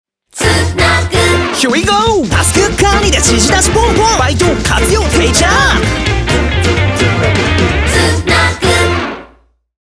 TVCM
SONG ROCK / POPS
Guitar